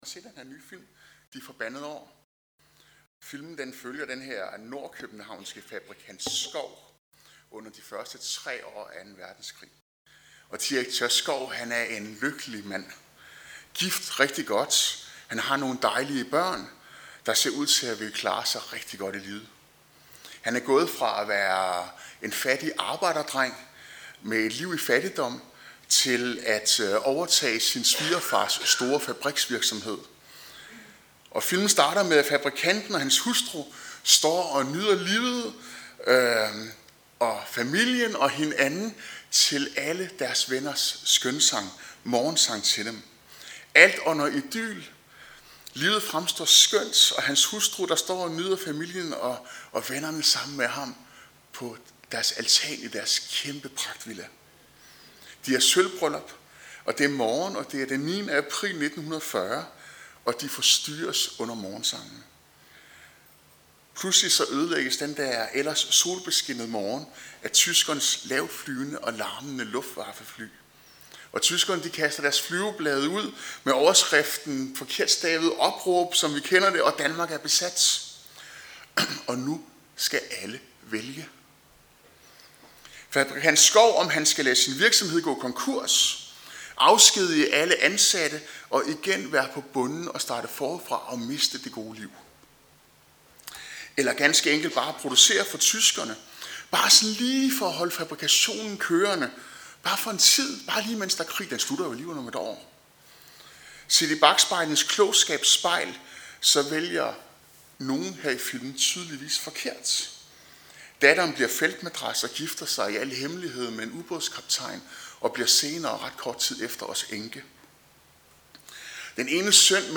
Prædikener fra Tønder Frikirke
Peters Brev kap. 4, Salme 38, Jobs Bog kap. 5, Prædikernes Bog kap. 4, Esajas Bog kap 49 Service Type: Gudstjeneste